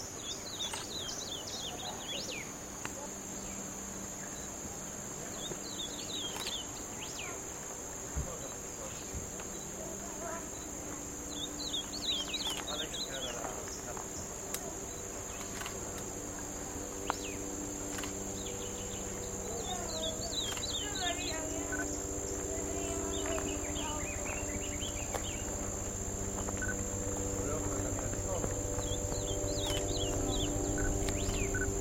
Ultramarine Grosbeak (Cyanoloxia brissonii)
Sex: Male
Life Stage: Adult
Province / Department: Entre Ríos
Location or protected area: Parque Nacional El Palmar
Condition: Wild
Certainty: Observed, Recorded vocal